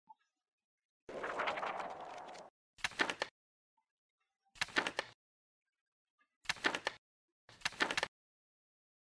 翻书音效.mp3